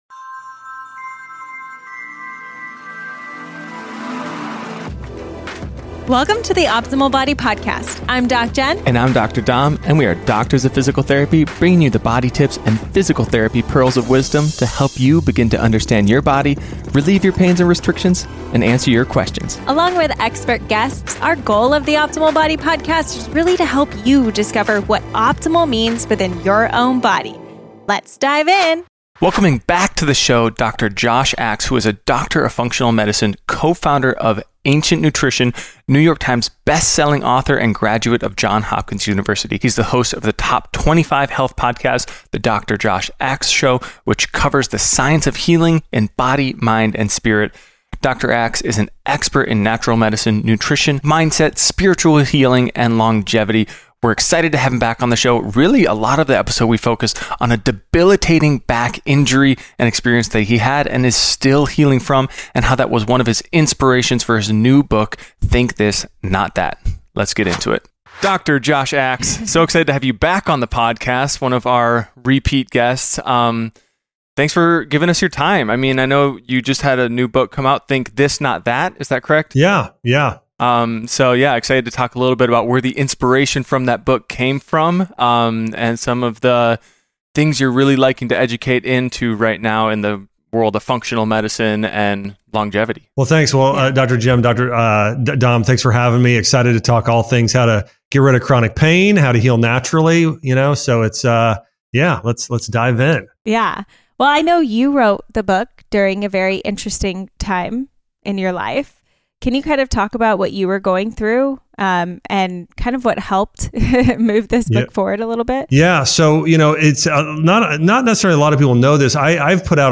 What You Will Learn in This Interview with Dr. Josh Axe: